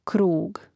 krȗg krug